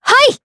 Leo-Vox_Attack4_jp.wav